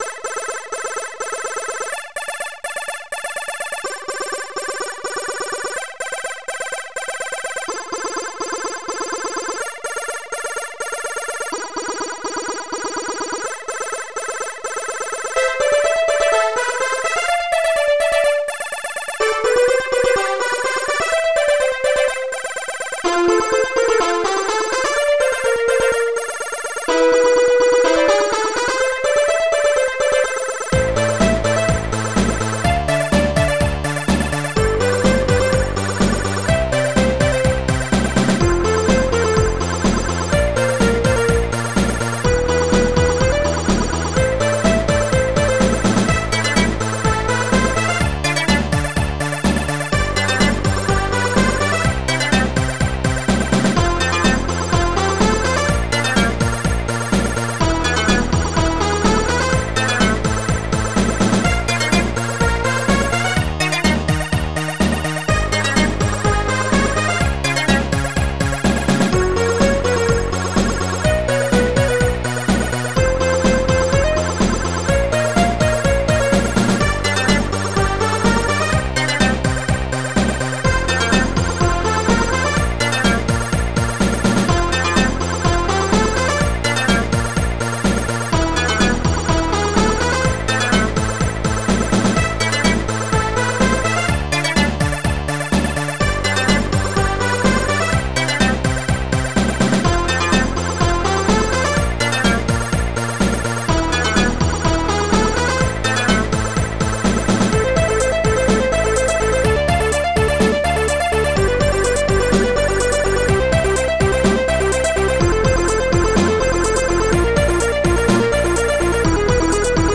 Protracker and family
ST-02:minimoog
ST-01:hihat2